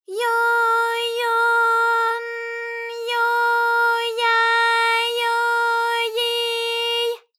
ALYS-DB-001-JPN - First Japanese UTAU vocal library of ALYS.
yo_yo_n_yo_ya_yo_yi_y.wav